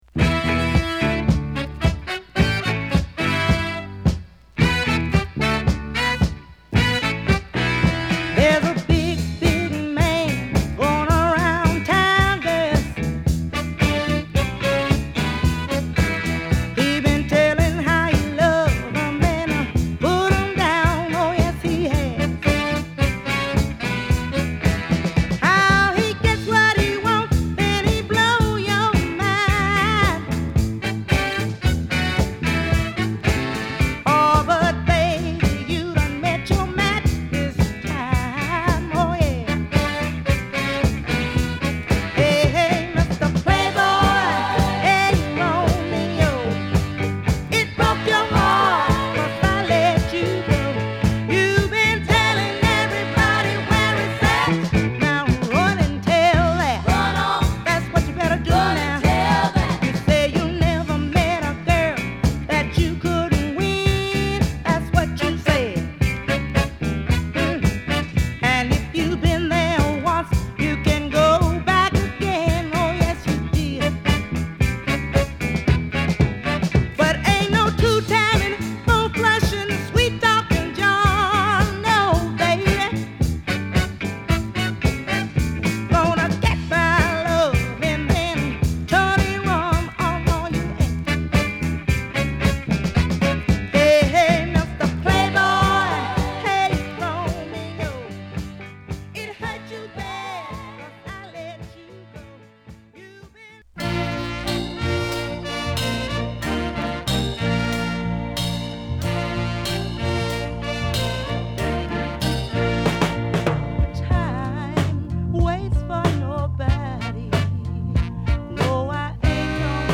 ボトムの太いファンキーでソウルフルな曲を満載